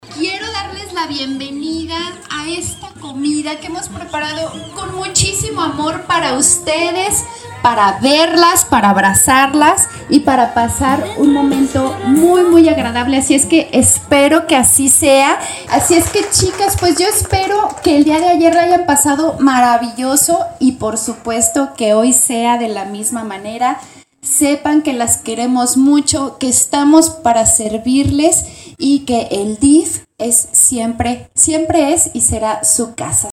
AudioBoletines
Irapuato, Gto. 11 de mayo del 2023 .- Más de 200 madres de familia de la tercera edad disfrutaron de una convivencia en el parque Irekua, con la que se reconoció su esfuerzo como jefas de familias.
Lorena Alfaro, presidenta municipal